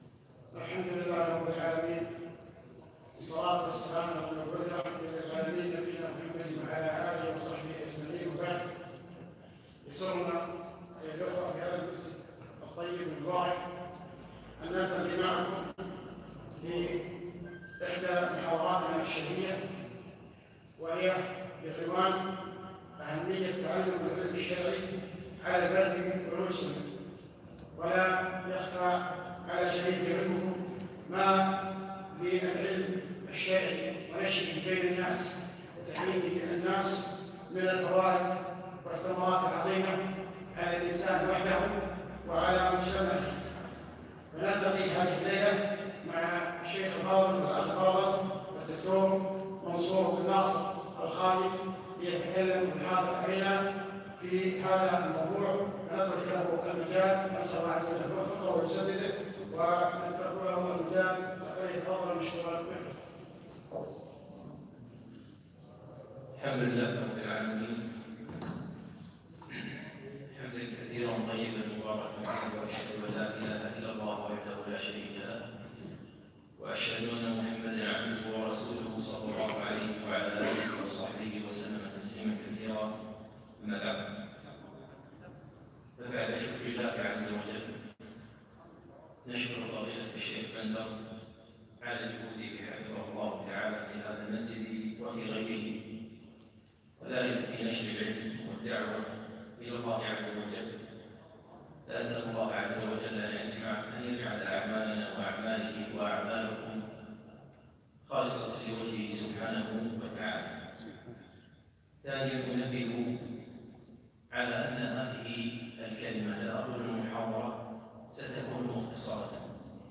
محاضرة - أهمية تعلم العلم الشرعي على الفرد والمجتمع